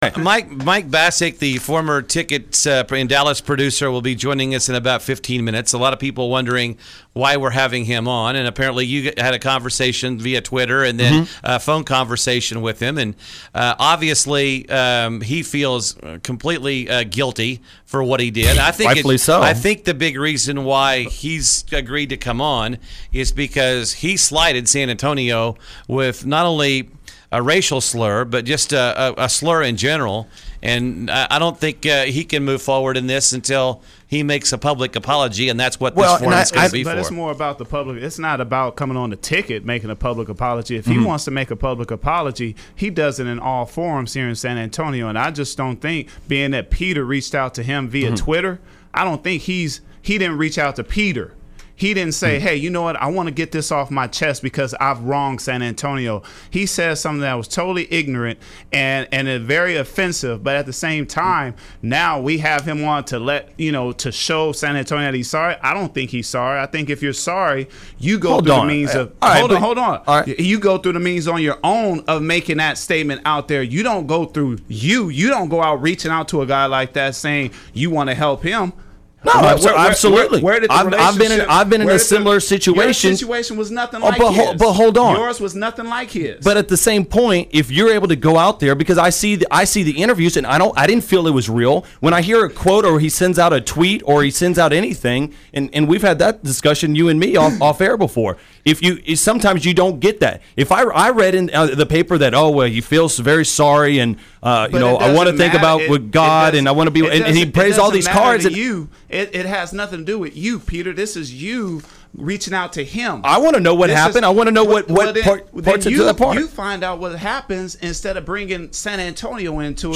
Mike called in and tried to apologize, but he got his wheels completely shot off by Bruce Bowen before, during and after the interview. Fortunately, the co-hosts of the show tried to be bigger men and allow Mike to try and apologize (which resulted in Bruce inferring that the hosts themselves were closet racists among other things).
An interesting listen, except for whenever Bowen decides to chime in.